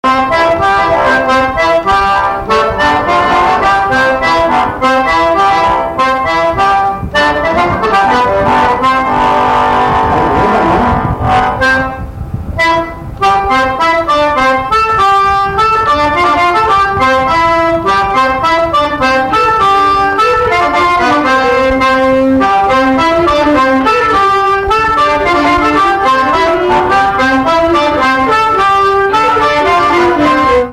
Instrumental
danse : polka
Pièce musicale inédite